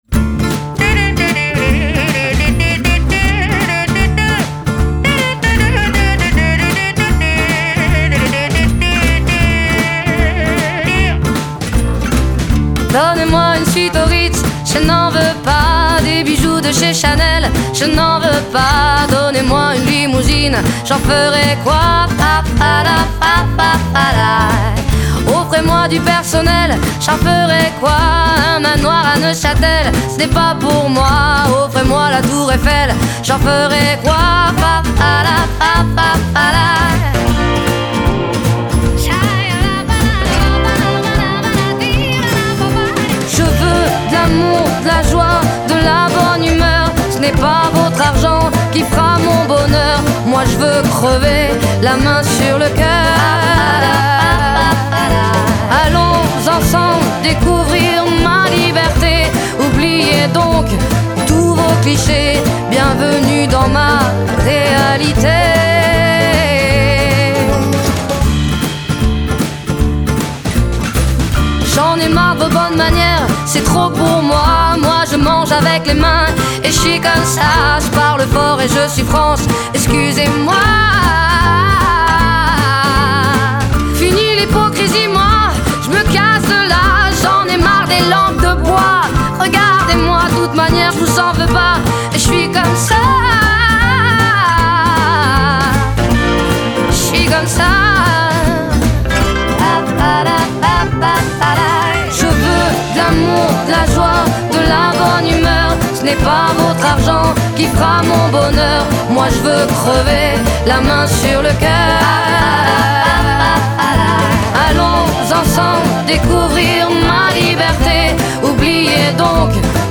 jazz
французский шансон